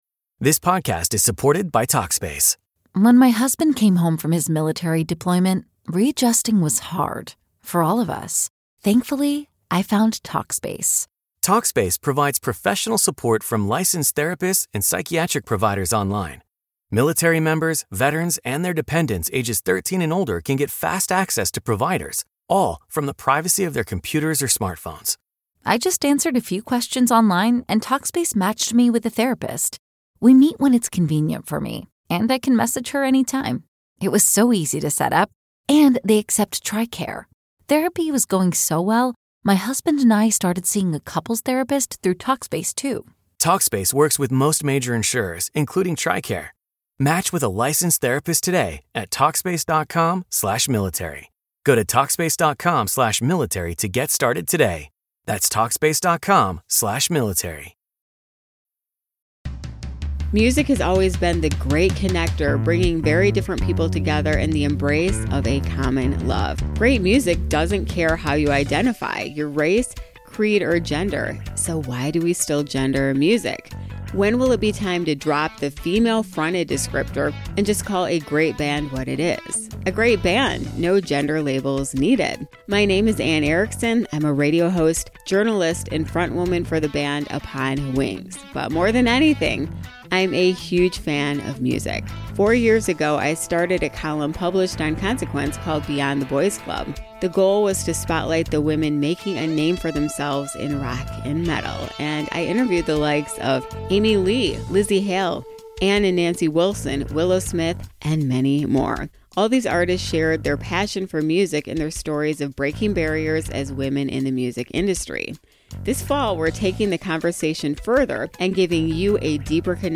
The interview series features deep-diving conversations from powerhouse women and nonbinary artists across all genres as they share insights into their creative journeys, the inside scoop on their latest projects, and their unique perspectives on the business of music at large.
Genres: Music, Music Commentary, Music Interviews
Trailer: